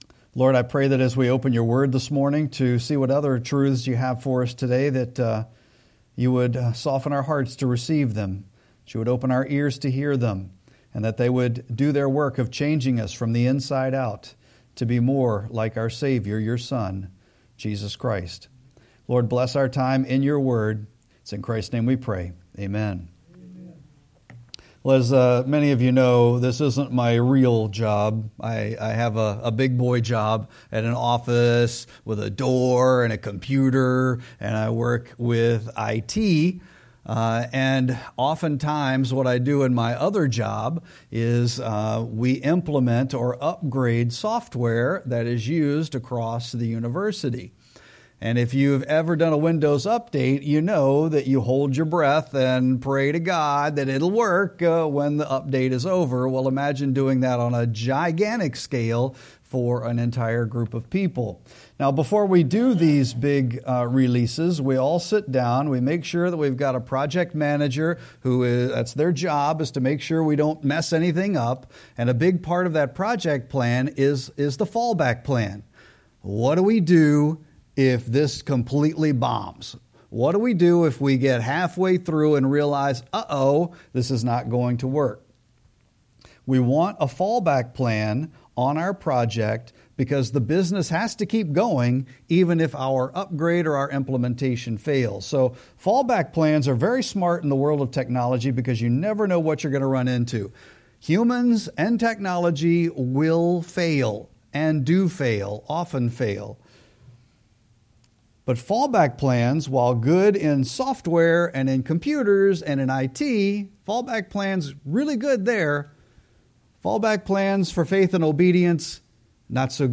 Sermon-5-18-25.mp3